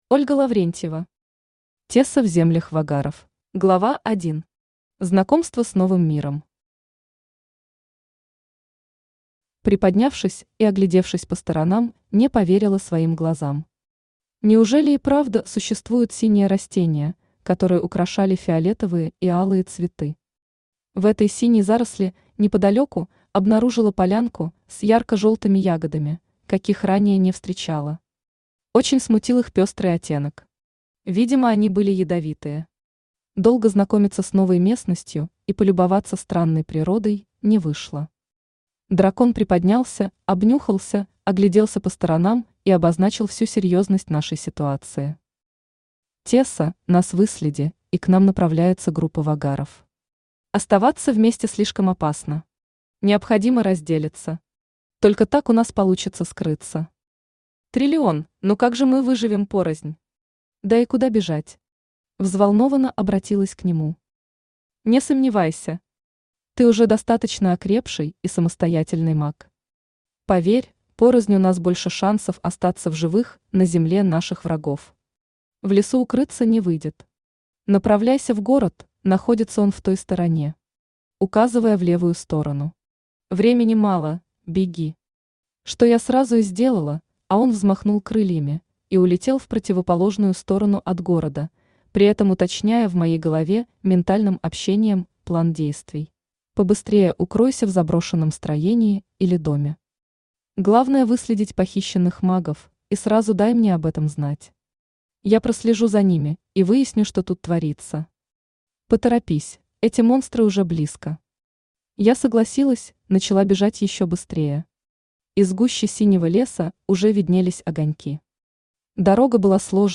Аудиокнига Тесса в землях Вагаров | Библиотека аудиокниг
Aудиокнига Тесса в землях Вагаров Автор Ольга Лаврентьева Читает аудиокнигу Авточтец ЛитРес.